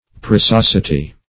Preciosity \Pre`ci*os"i*ty\, n.